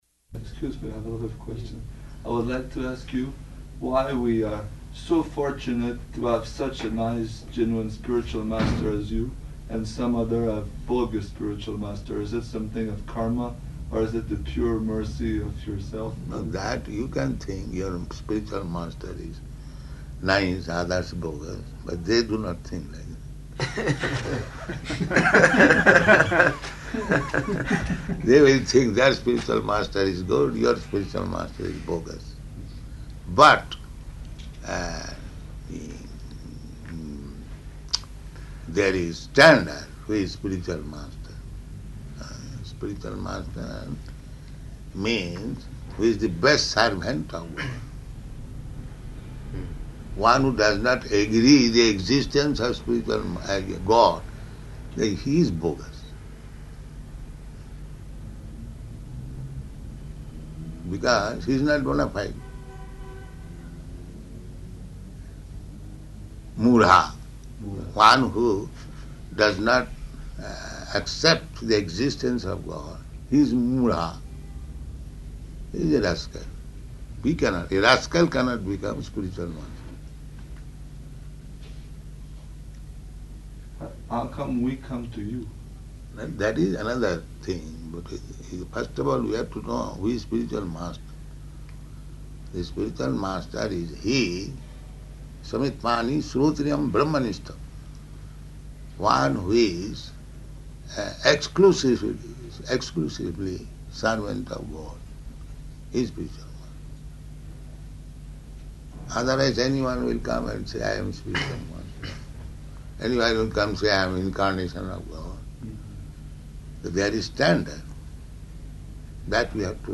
Room Conversation with Sanskrit Professor
-- Type: Conversation Dated: February 12th 1975 Location: Mexico City Audio file